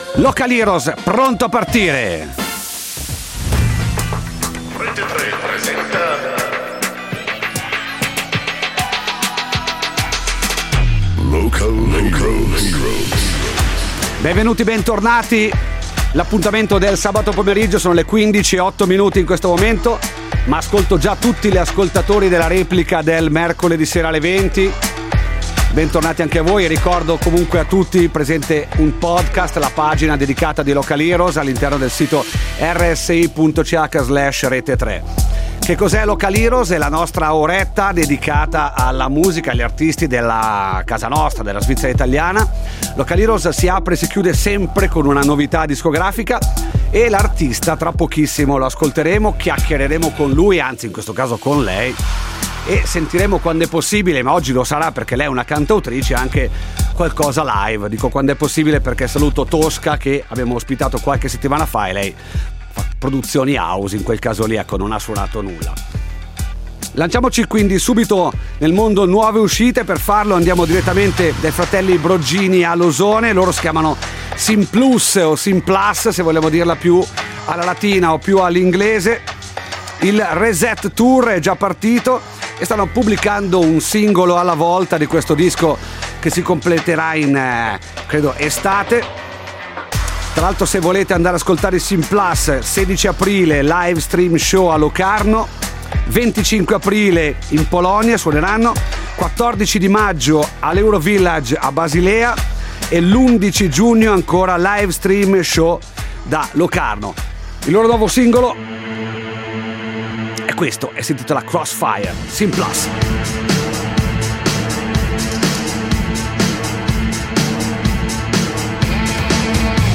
cantautrice ticinese, si presenta con chitarra al seguito